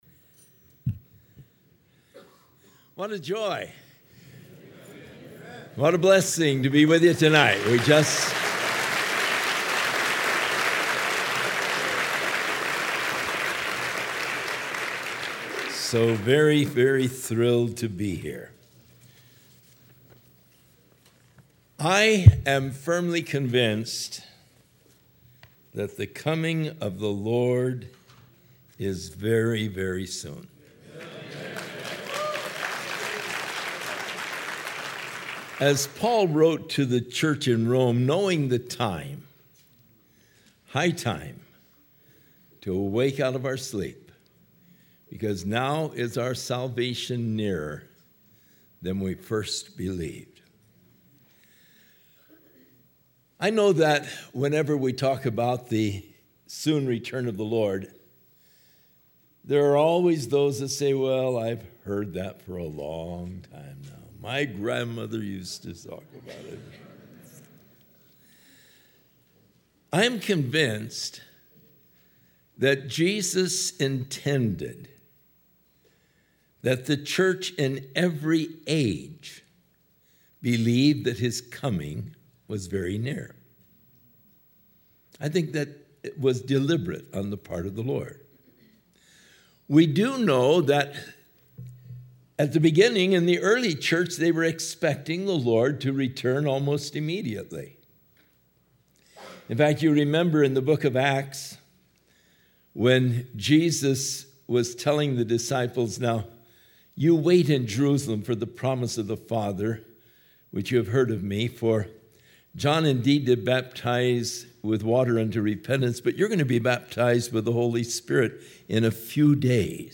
Session 4 Speaker: Chuck Smith Series: 2006 DSPC Conference: Pastors & Leaders Date: April 26, 2006 Home » Sermons » Session 4 Share Facebook Twitter LinkedIn Email Topics: Session 4 « Session 3 Session 5 »